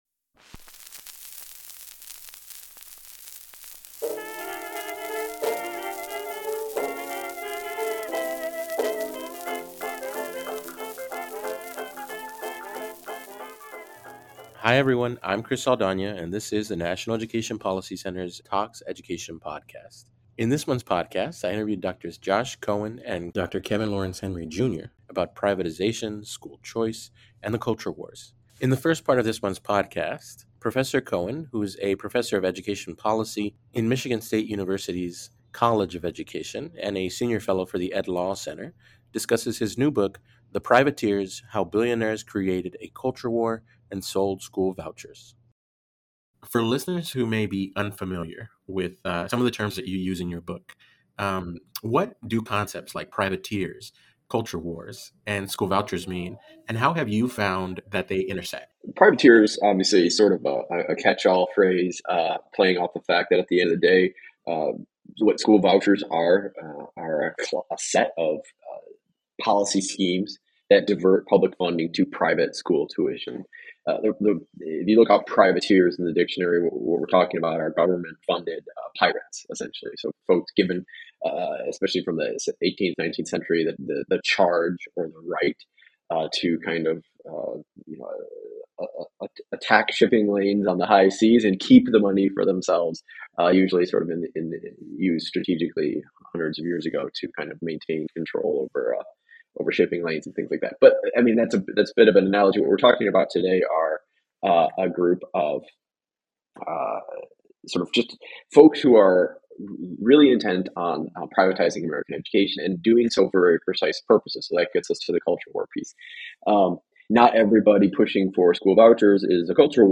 NEPC Talks Education: An Interview